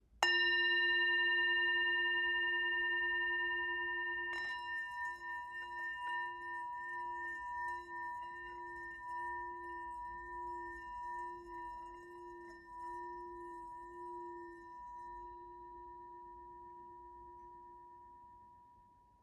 Tibetská mísa Chuto malá
Tepaná tibetská mísa Chuto o hmotnost 456 g a malá palička s kůží.
Způsob provedení mísy Tepaná
tibetska_misa_m52.mp3